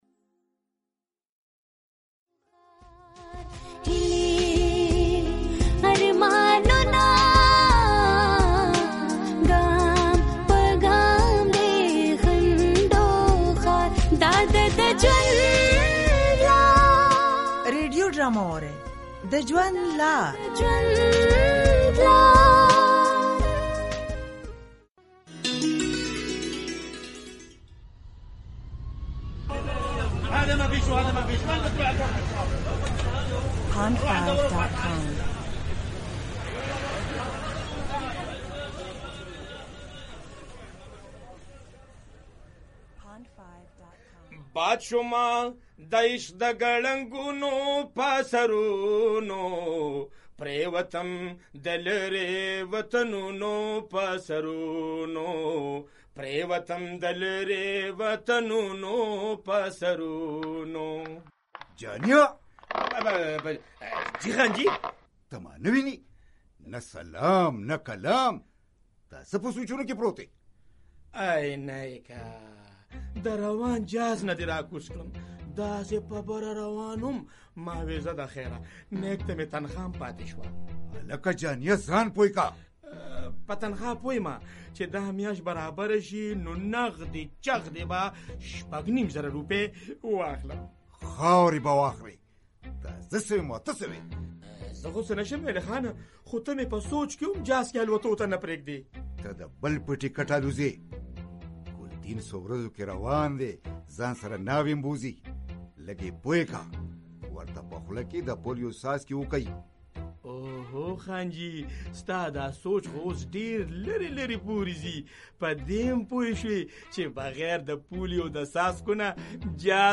Polio Drama Episode 8